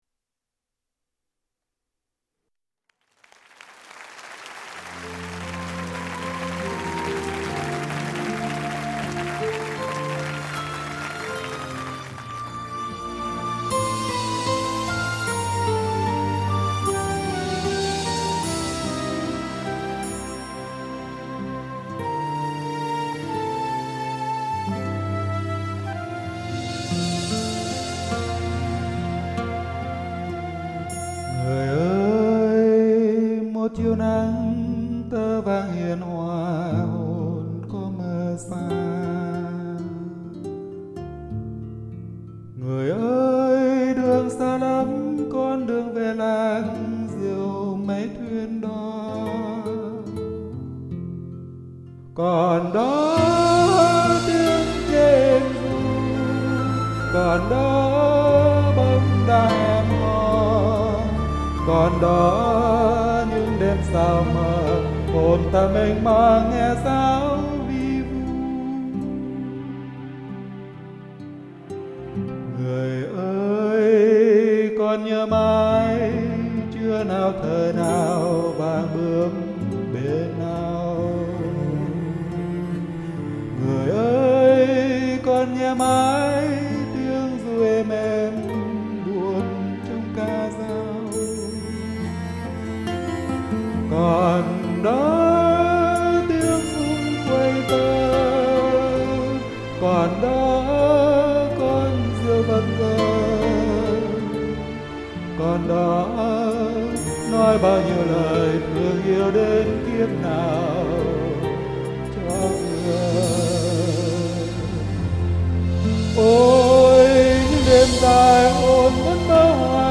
Giọng anh nhẹ hơn gió thoảng , ngọt hơn đường mật 61.gif